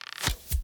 Bow Attack 2.ogg